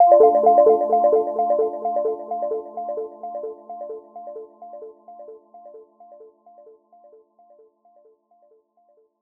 Echoes_D_01.wav